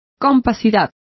Complete with pronunciation of the translation of compactness.